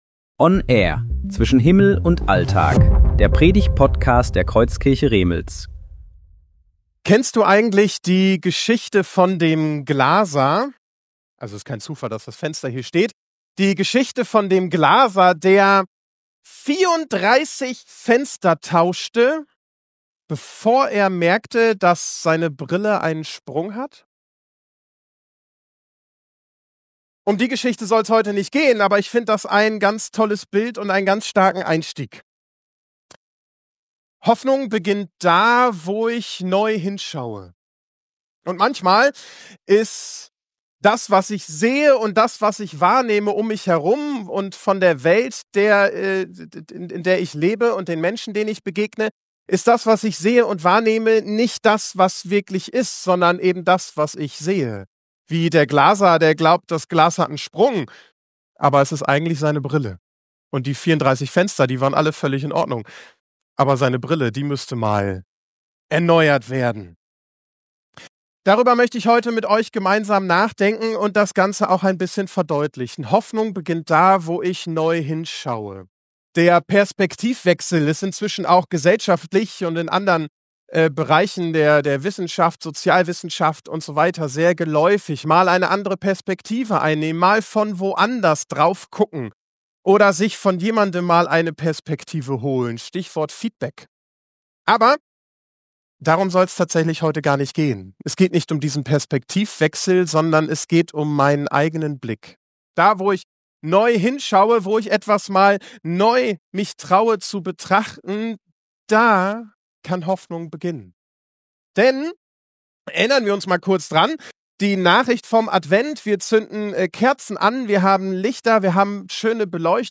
Predigten
Predigtserie: Gottesdienst